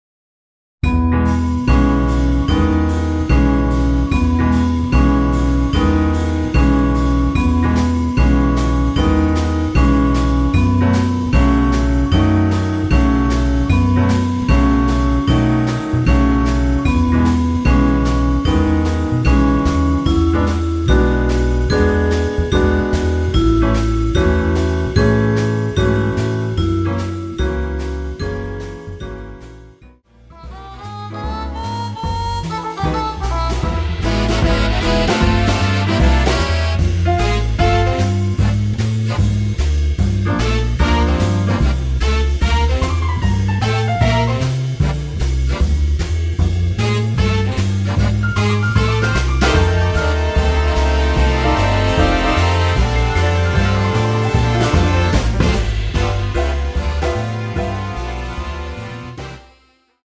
약간 느리게로 편집한 MR 입니다.
앞부분30초, 뒷부분30초씩 편집해서 올려 드리고 있습니다.
곡명 옆 (-1)은 반음 내림, (+1)은 반음 올림 입니다.